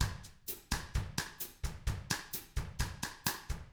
129BOSSAF1-R.wav